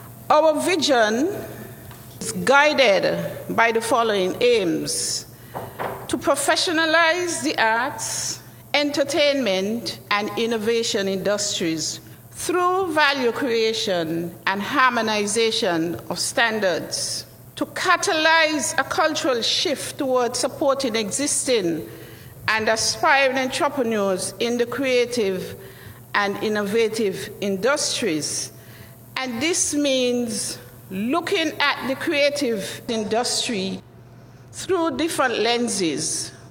On Friday October 29th the Ministry of Entertainment, Entrepreneurship and Talent Development (MoENT) held a Creative Forum at the Marriott Resort in St. Kitts.
During the forum, Permanent Secretary in the Ministry, Mrs. Cheryleann Pemberton highlighted some of the aims of the Ministry to attendees of the forum.